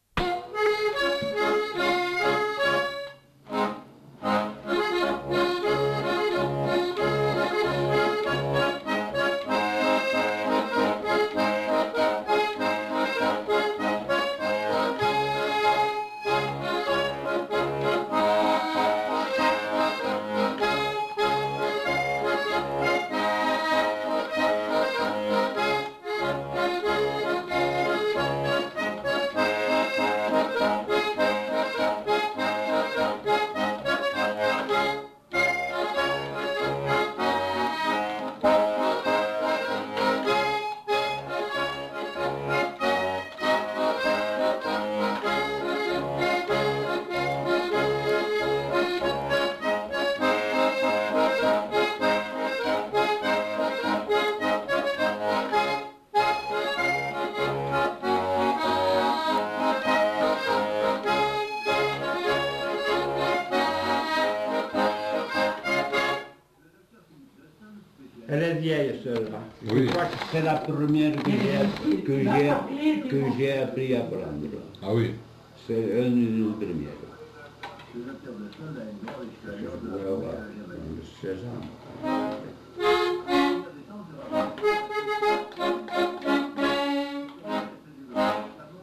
Aire culturelle : Bigorre
Lieu : Aulon
Genre : morceau instrumental
Instrument de musique : accordéon diatonique
Danse : polka